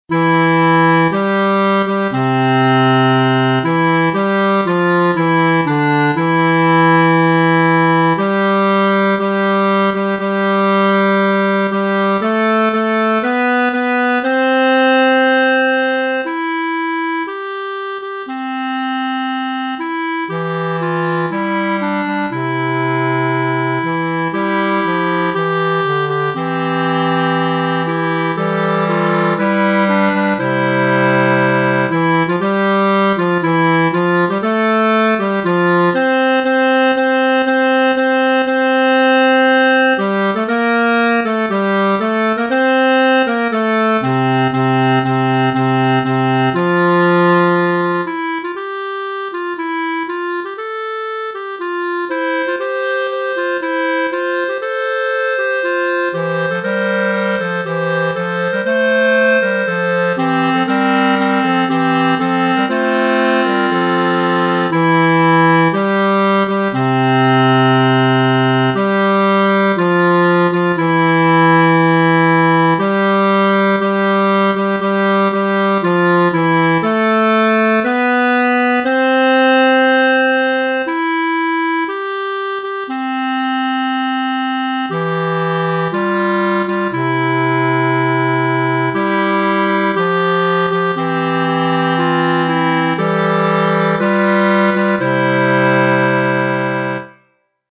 Fugue